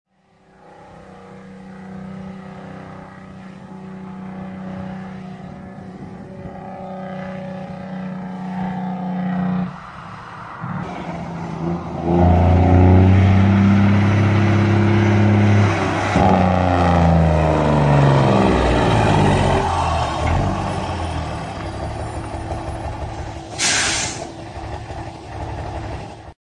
A braking system specific to diesels, known as a Jacobs brake, can use the engine to assist the vehicle's air brakes.  The driver presses a button to shut off the fuel to certain cylinders and exhaust their compressed air, loudly.
diesel-truck-jake-brake-and-air-brake-63219.mp3